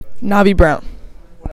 Pronunciations